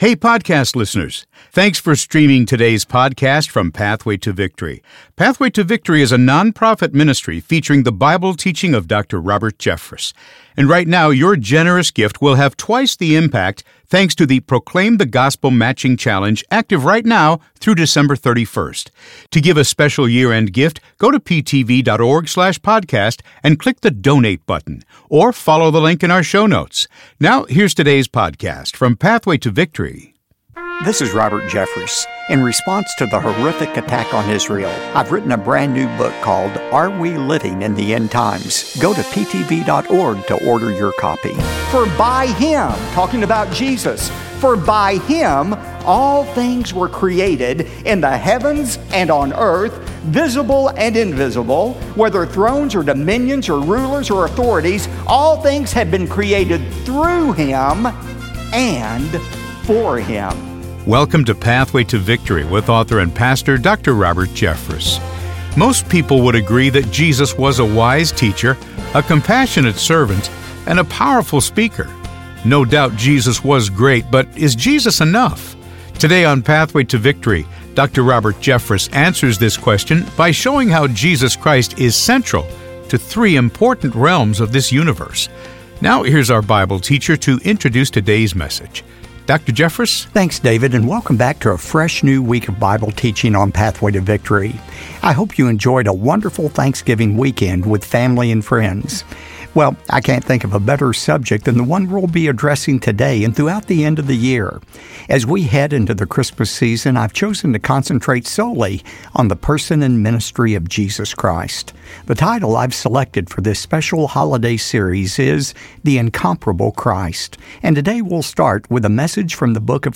Jesus Christ Creation Sufficiency Colossians Christmas Bible Teaching Robert Jeffress